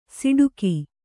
♪ siḍuki